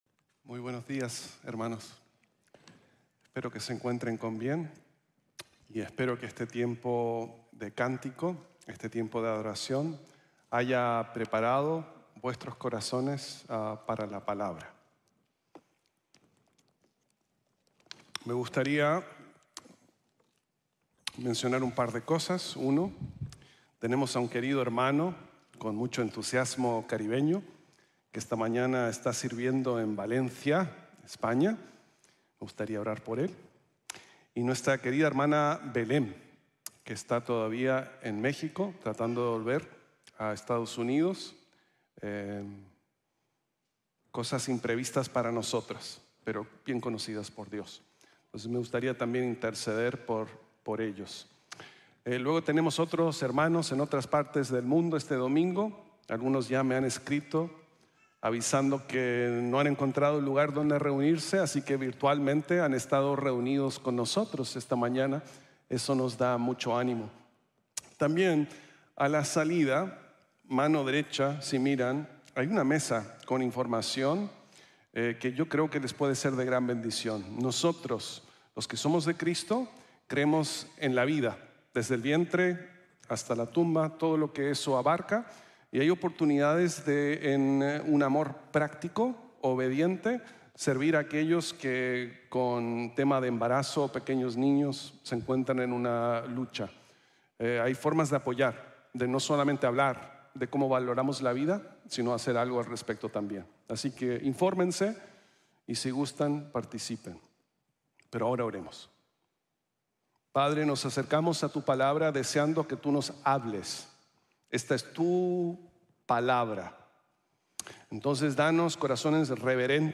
Si Le Importa | Sermón | Grace Bible Church